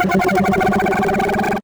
2600_charge.ogg